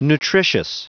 Prononciation du mot nutritious en anglais (fichier audio)
nutritious.wav